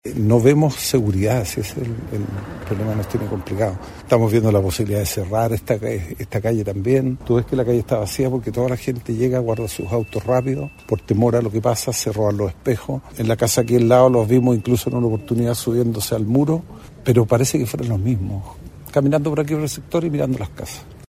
Un vecino y frente a lo ocurrido, señaló que la falta de seguridad permitió este nuevo delito.